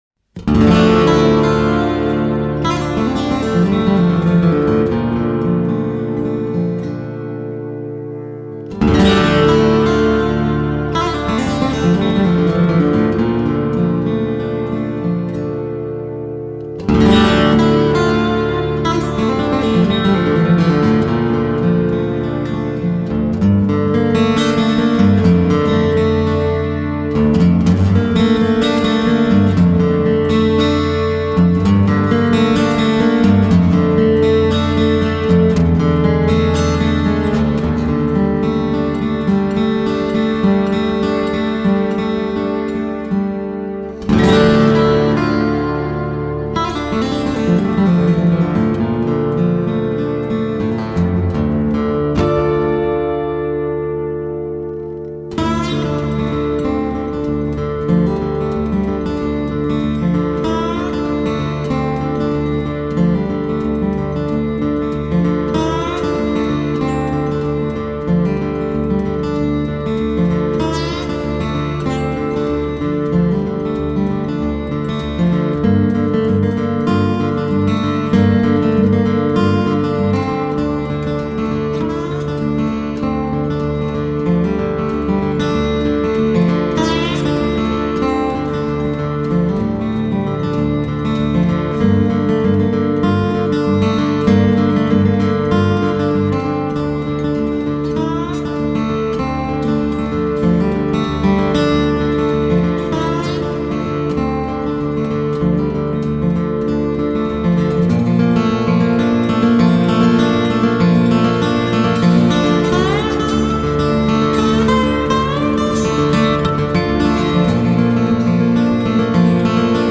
guitarist/singer